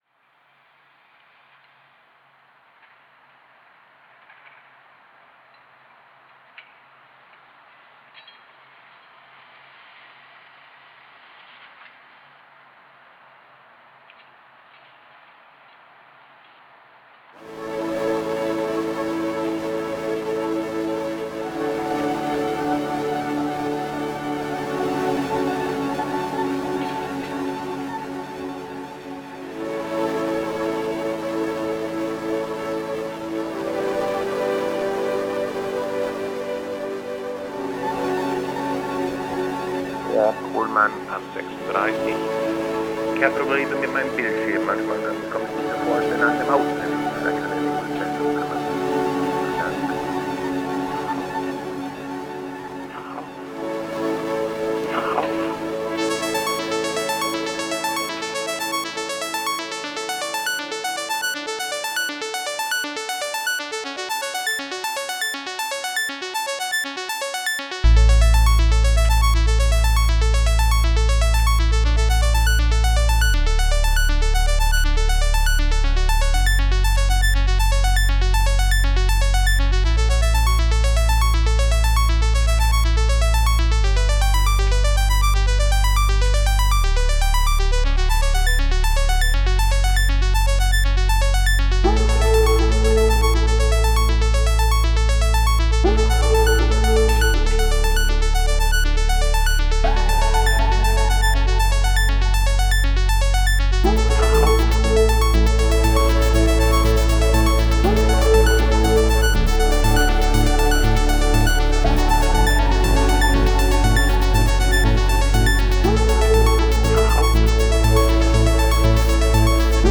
Mainly DN2 with just a very few samples coming from DT2:
Ended up feeling like a very long intro to an otherwise anticlimactic song…